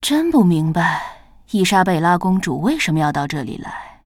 文件 文件历史 文件用途 全域文件用途 Cyrus_fw_03.ogg （Ogg Vorbis声音文件，长度4.1秒，101 kbps，文件大小：50 KB） 源地址:游戏语音 文件历史 点击某个日期/时间查看对应时刻的文件。 日期/时间 缩略图 大小 用户 备注 当前 2018年3月10日 (六) 17:54 4.1秒 （50 KB） 地下城与勇士  （ 留言 | 贡献 ） 源地址:游戏语音 您不可以覆盖此文件。